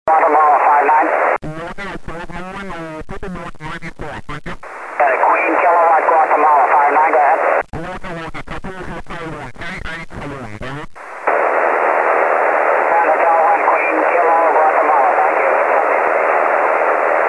6m5(MP3 168KB)　　　SSB　で当局とW　との交信。少しコンディションが落ちてきた・